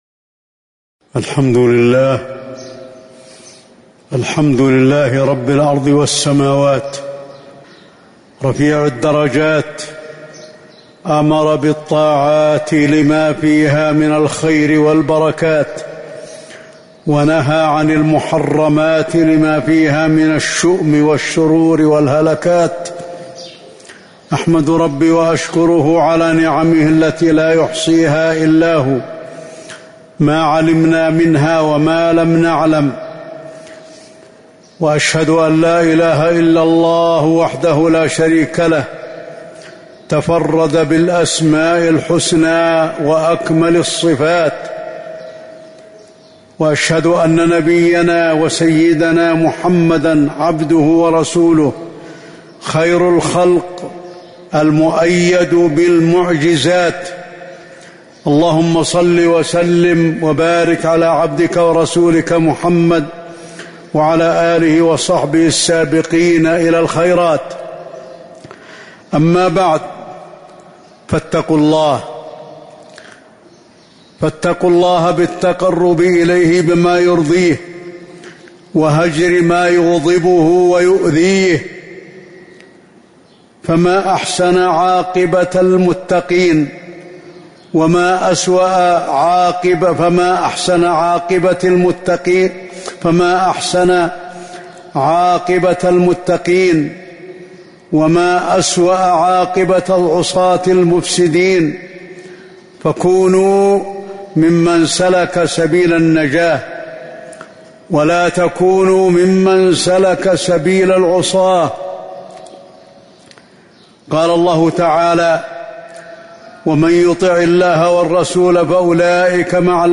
تاريخ النشر ٢٨ محرم ١٤٤٤ هـ المكان: المسجد النبوي الشيخ: فضيلة الشيخ د. علي بن عبدالرحمن الحذيفي فضيلة الشيخ د. علي بن عبدالرحمن الحذيفي إصلاح الأرض بالطاعات